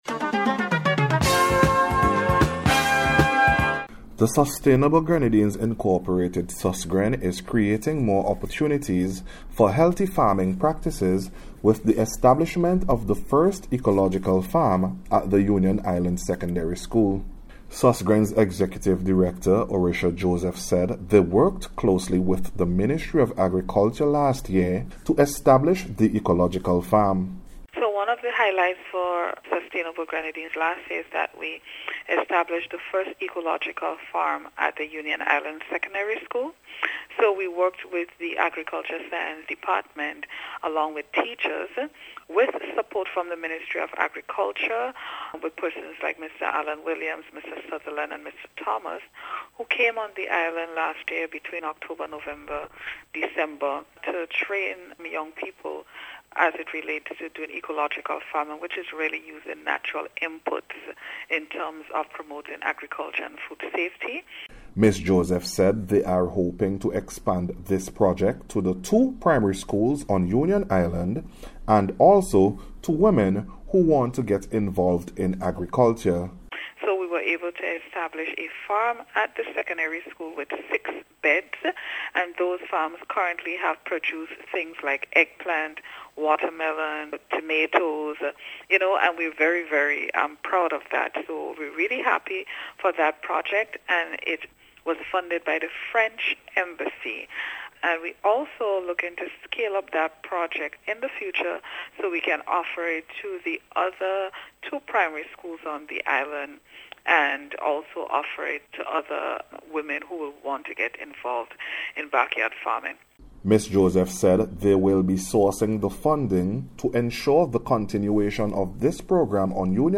SUSGREN-ECOLOGICAL-FARMING-REPORT.mp3